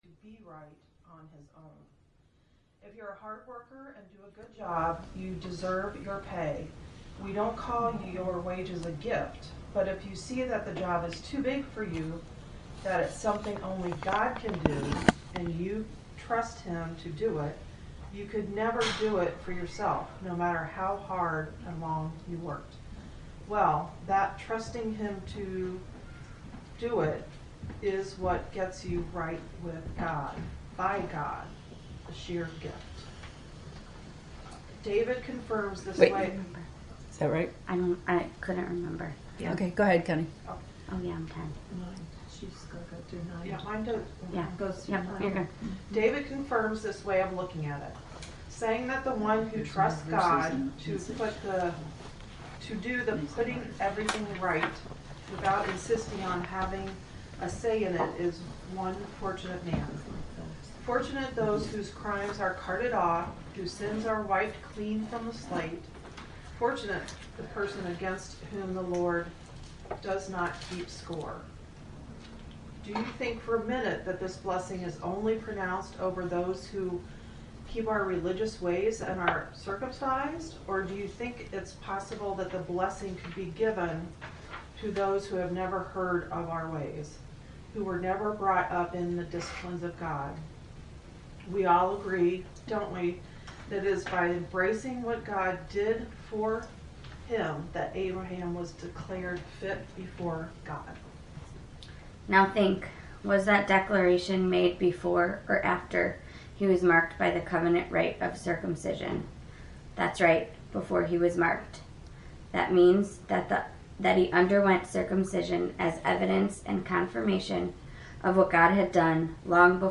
To listen to the lesson 5 lecture, “The Necessity of Death,” click below: